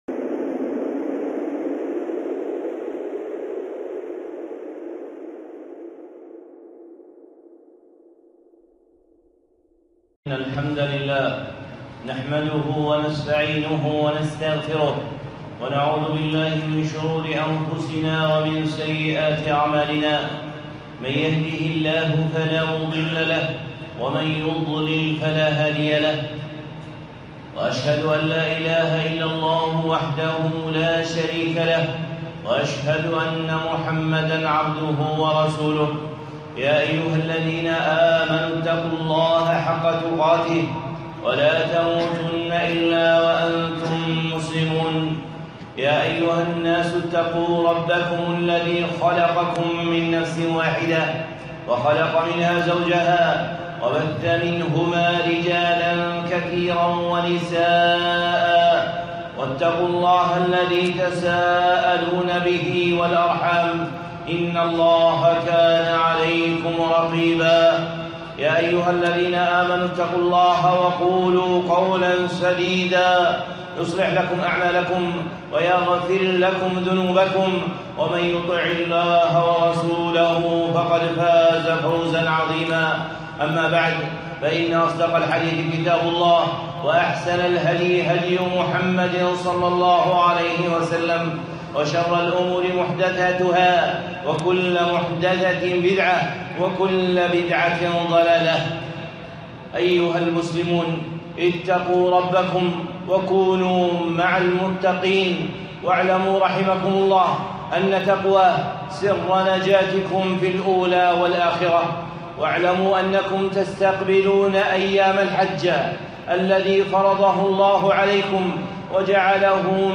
خطبة (حجوا عباد الله)